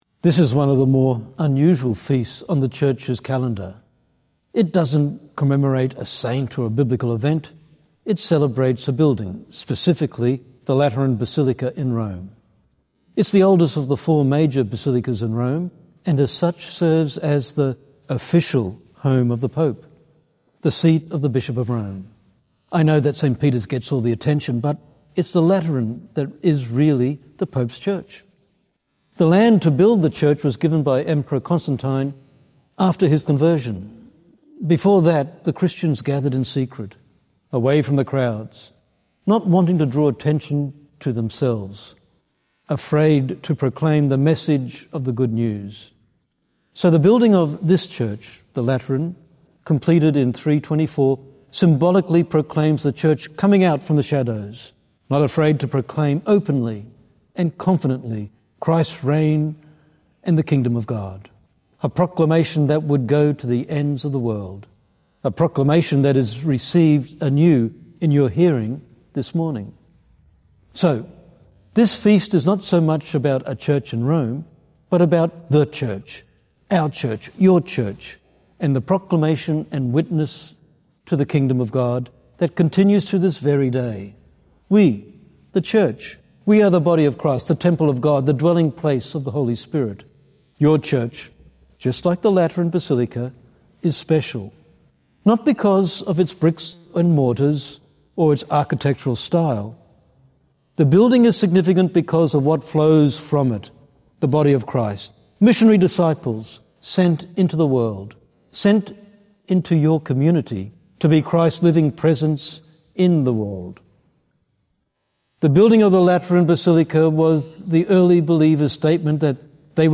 Two-Minute Homily